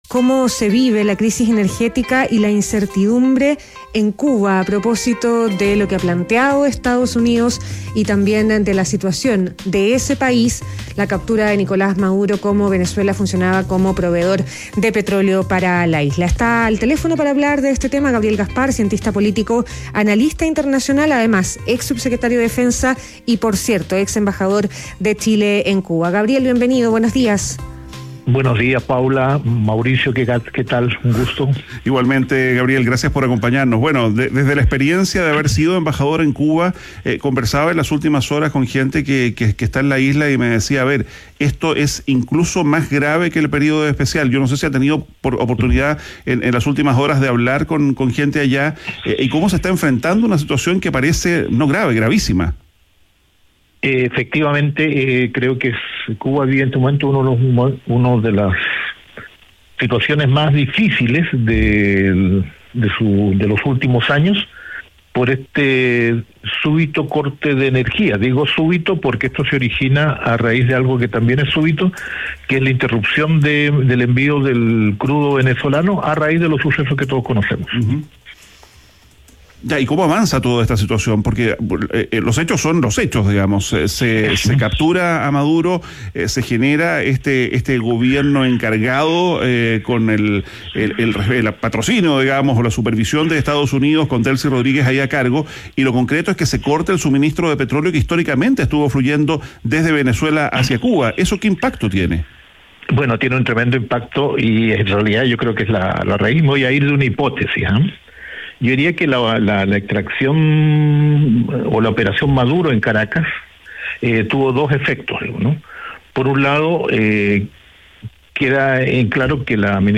Exembajador de Chile en La Habana sostuvo en ADN Hoy que el corte del petróleo venezolano marca un punto de inflexión y que Washington apuesta por el estrangulamiento económico antes que una salida militar.